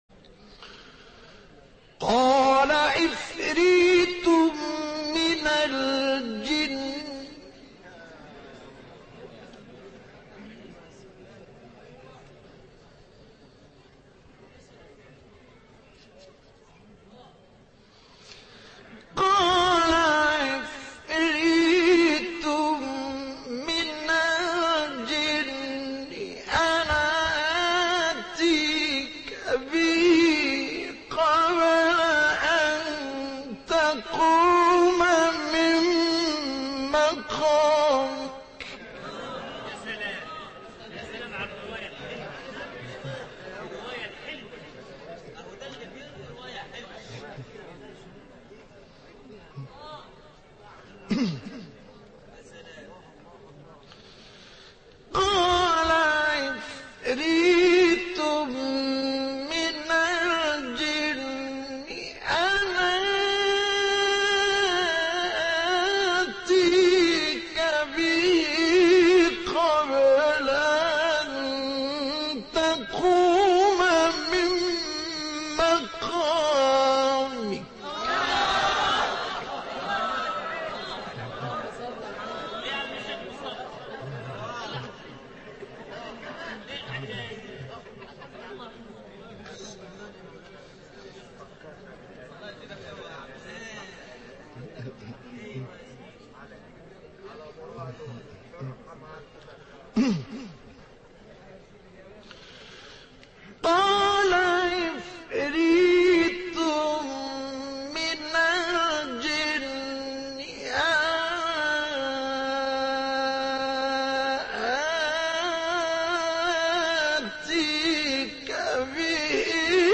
به گزارش ایکنا، چهار اجرای متفاوت از تلاوت آیه 39 سوره مبارکه نمل با صوت مصطفی اسماعیل، قاری قرآن کریم از کشور مصری در کانال تلگرامی اکبرالقراء منتشر شده است.
تلاوت آیه 39 سوره نمل در سال 1962 میلادی